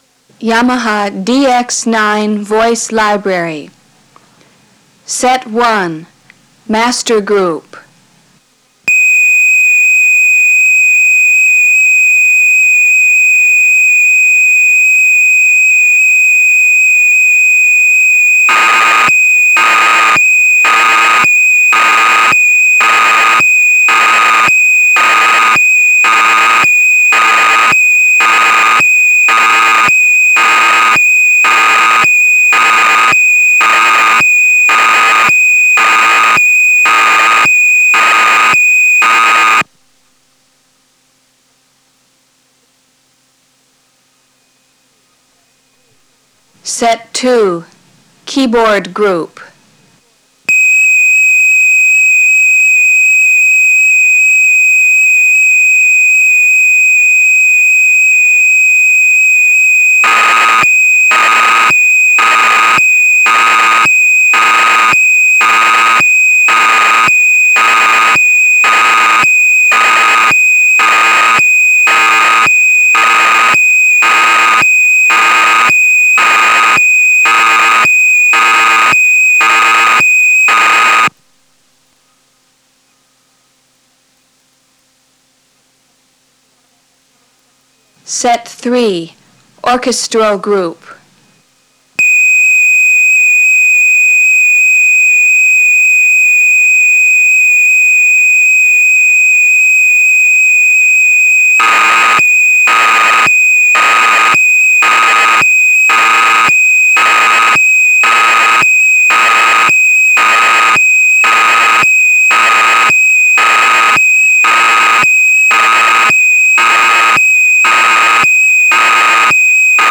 a FLAC file of he hard to find Yamaha Data Tape 2
DX9VoiceDataTape2.flac